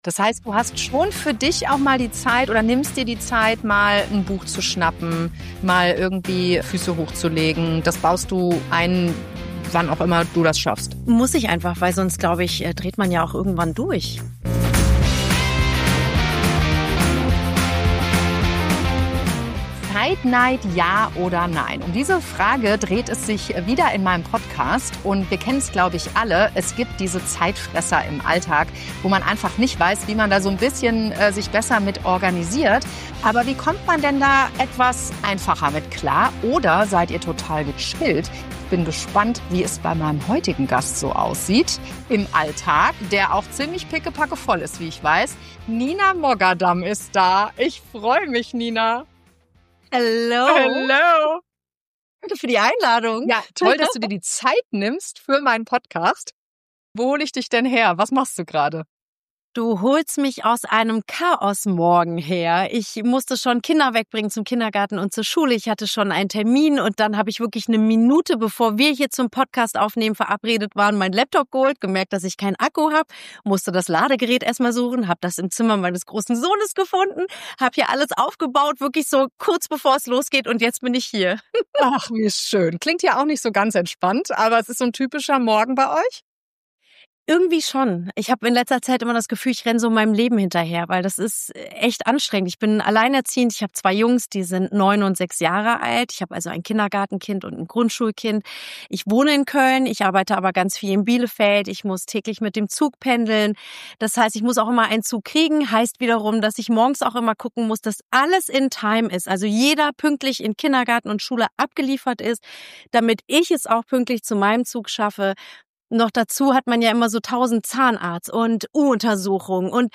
In dieser Folge spreche ich mit Moderatorin und alleinerziehender Mama Nina Moghaddam über das Jonglieren zwischen Termindruck und Familienzeit, über ihre Morgen voller Chaos, das Leben im Mehrgenerationenhaus und warum To-do-Listen auf Papier manchmal besser funktionieren als jede App.